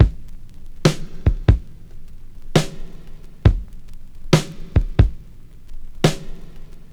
• 69 Bpm Drum Beat G Key.wav
Free breakbeat sample - kick tuned to the G note.
69-bpm-drum-beat-g-key-GtS.wav